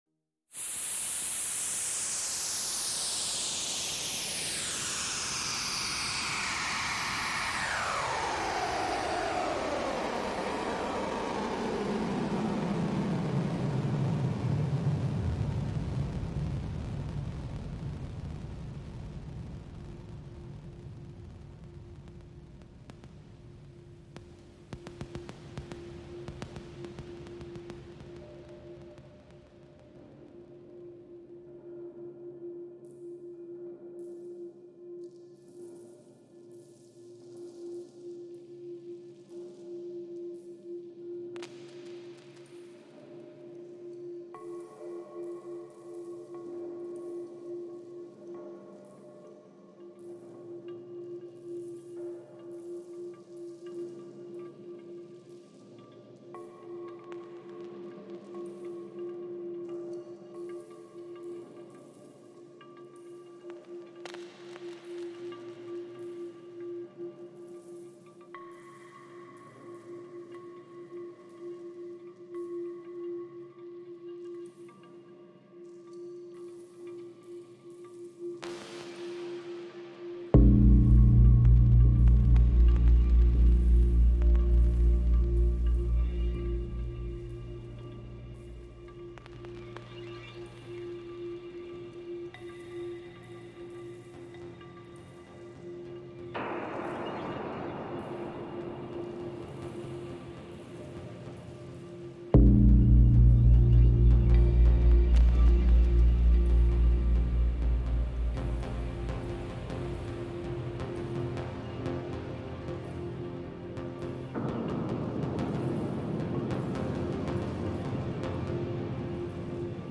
Incontro con il compositore, performer elettroacustico, ingegnere del suono e docente